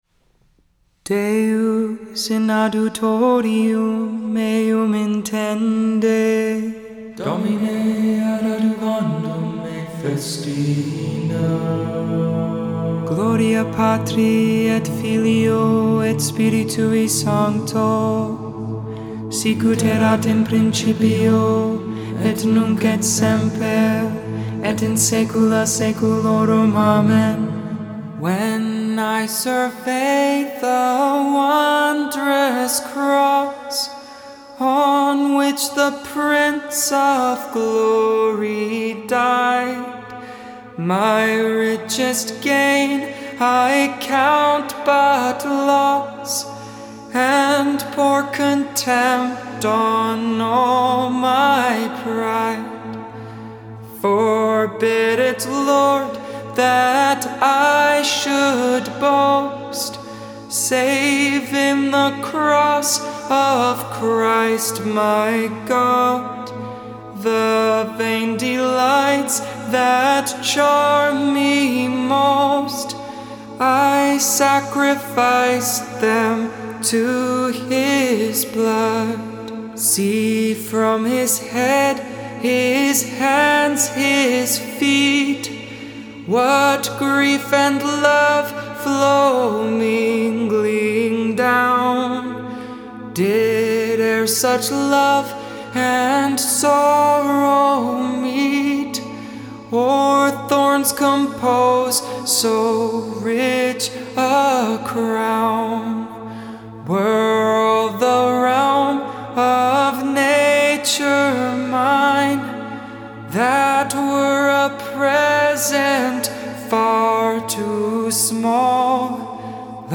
Vespers, Evening Prayer for the 1st Wednesday of Lent.